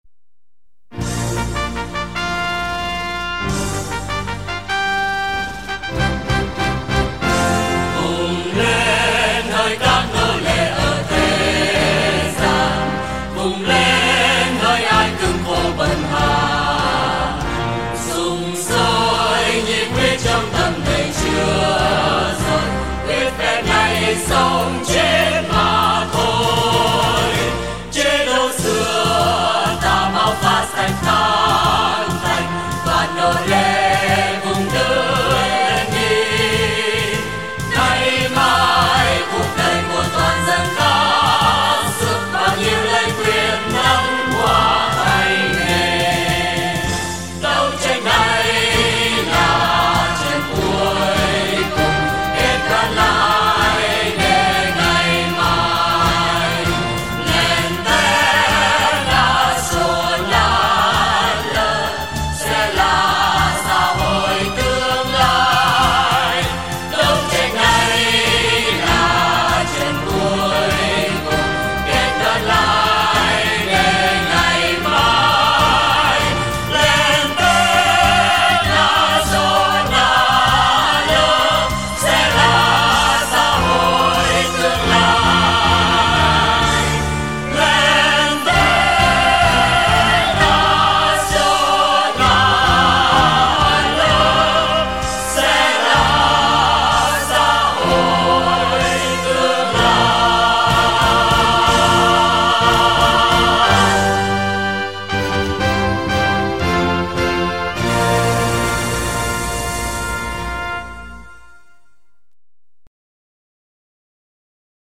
File nhạc có lời